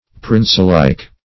Princelike \Prince"like`\, a.